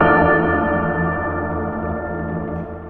without warning 21 x offset bell.wav